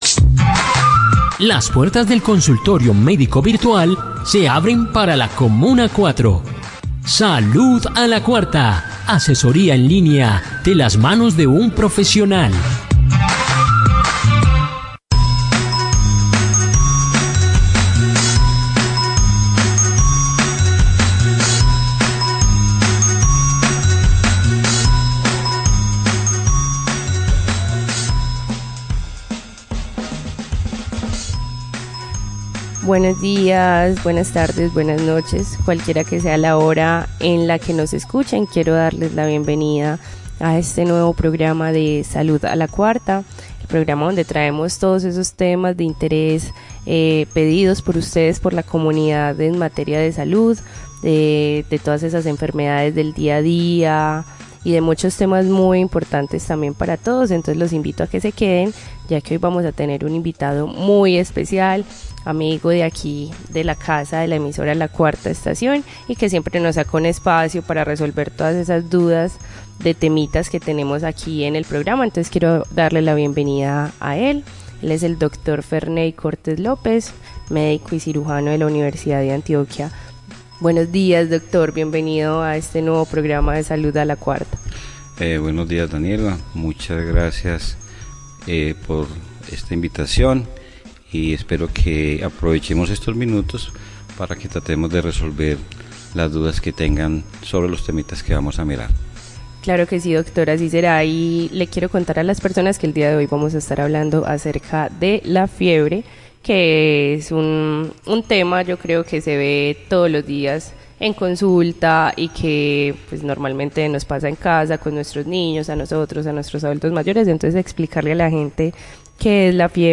Acompáñanos en este nuevo capítulo, donde conversaremos con un profesional sobre la fiebre y sus generalidades, en qué escenarios debemos preocuparnos por la fiebre y acudir a un centro de salud.